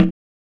percussion 30.wav